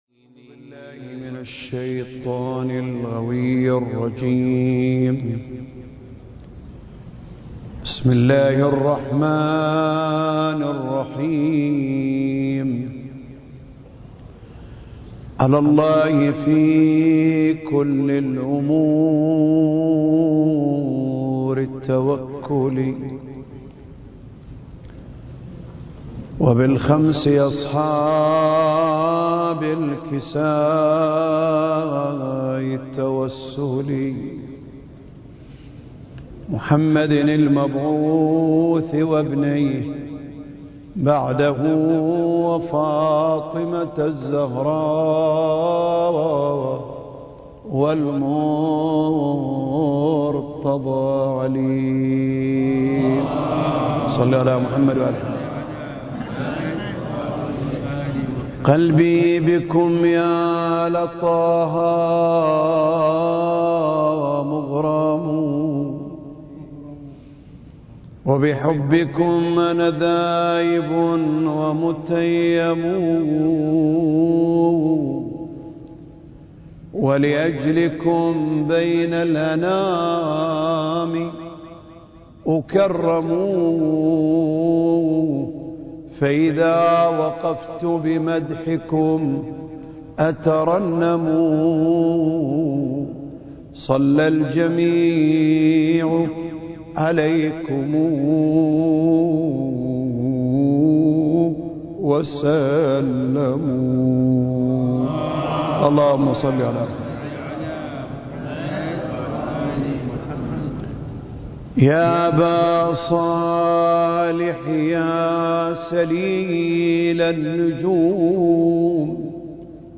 سلسلة محاضرات: آفاق المعرفة المهدوية (2) المكان: الأوقاف الجعفرية بالشارقة التاريخ: 2023